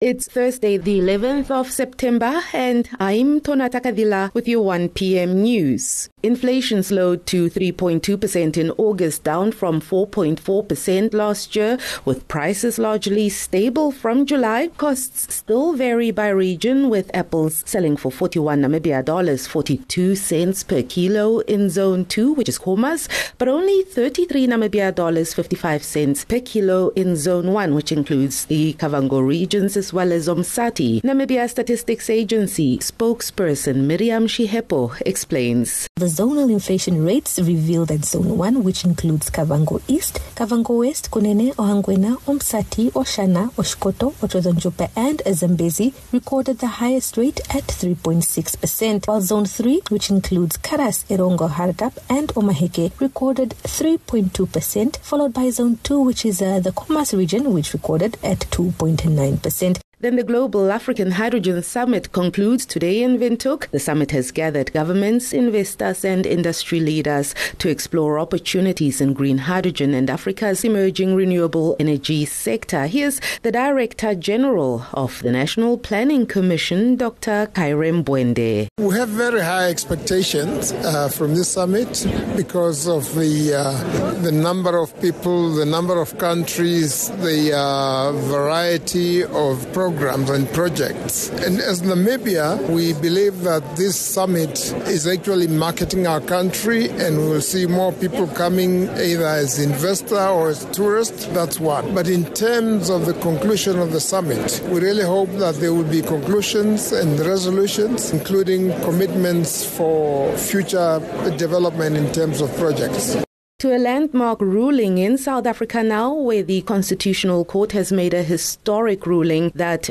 11 Sep 11 September - 1 pm news